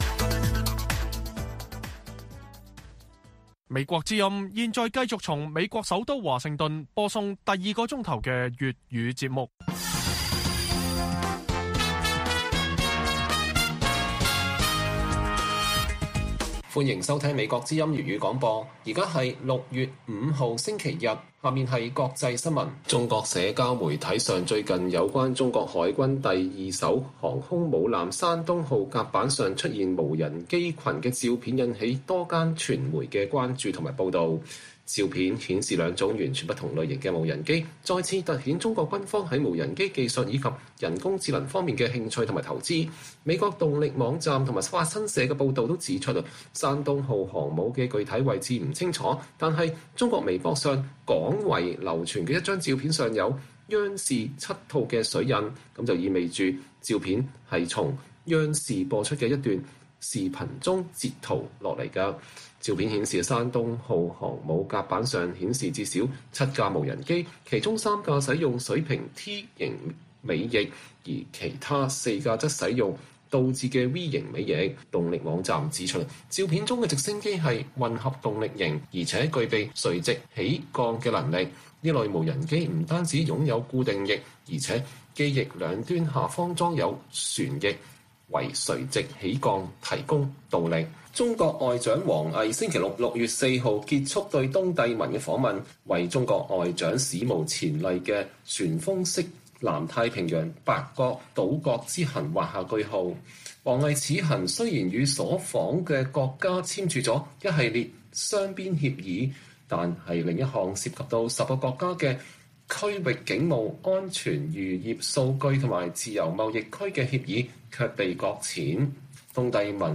北京時間每晚10－11點 (1400-1500 UTC)粵語廣播節目。內容包括國際新聞、時事經緯和英語教學。